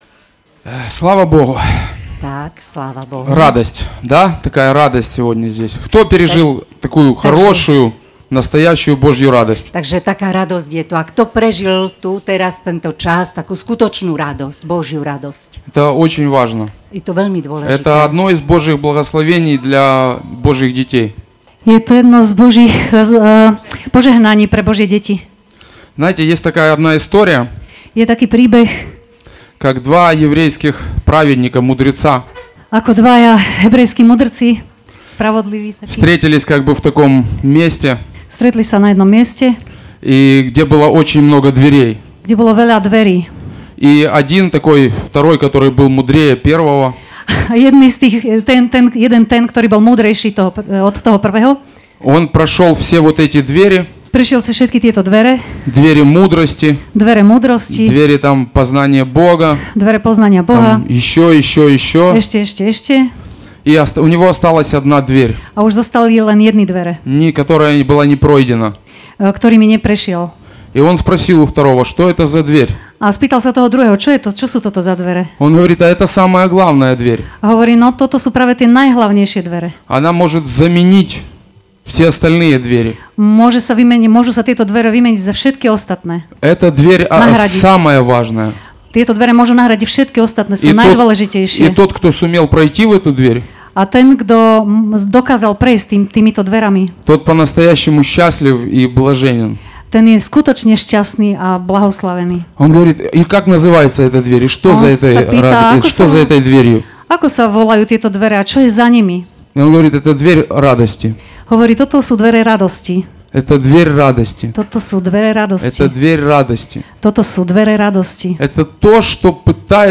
Vypočuj si nedeľné kázne zo zhromaždení Radostného Srdca v Partizánskom.